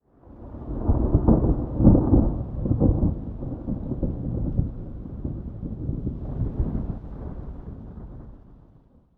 thunderfar_7.ogg